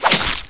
meleehit2.wav